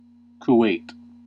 Kuwait City (/kʊˈwt/
En-us-Kuwait.ogg.mp3